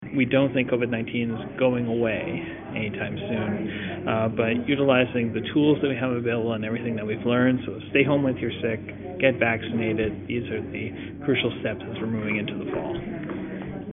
Dr. Toumishey told Quinte News that it’s important people get the vaccine that is available.